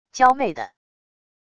娇媚的wav音频